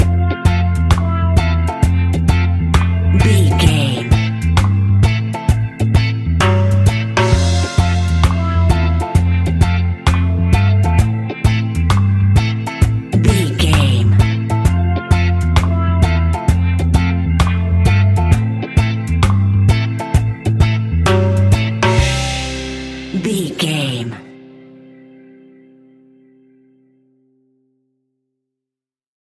Classic reggae music with that skank bounce reggae feeling.
Ionian/Major
reggae instrumentals
laid back
chilled
off beat
drums
skank guitar
hammond organ
percussion
horns